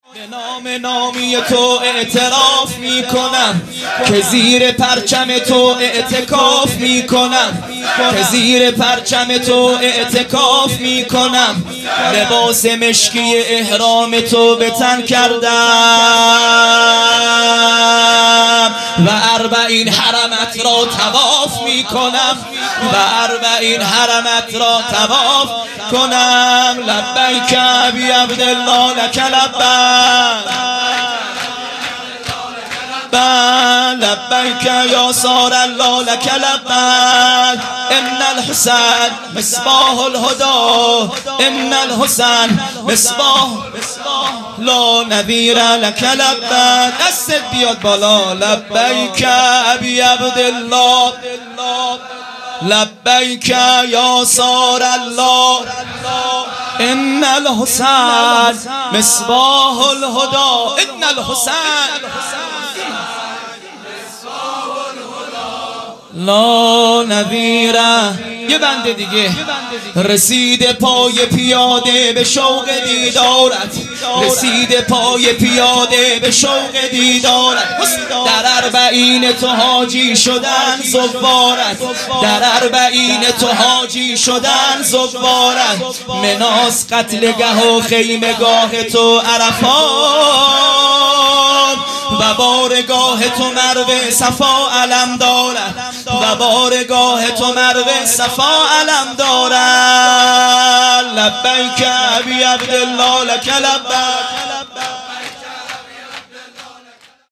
هیئت مکتب الزهرا(س)دارالعباده یزد
به نام نامی تو اعتراف میکنم مداح